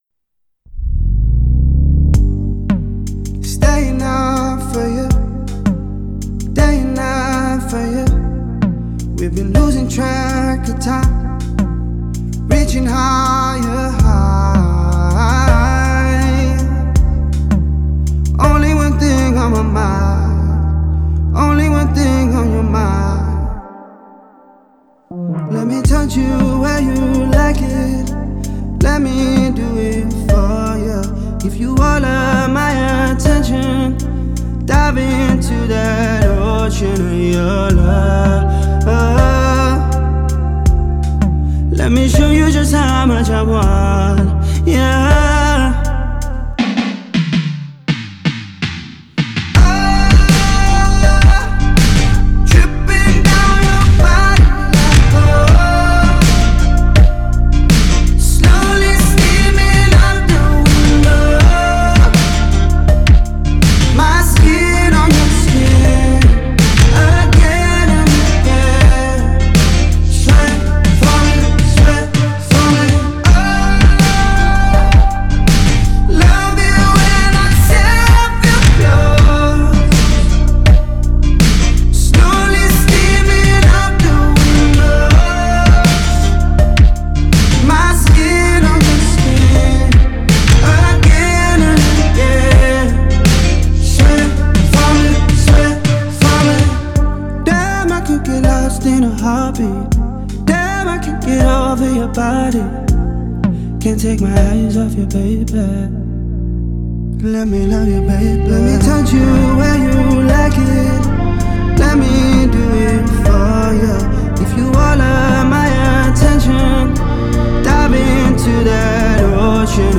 это захватывающий трек в жанре R&B
современными электронными элементами и мощными битами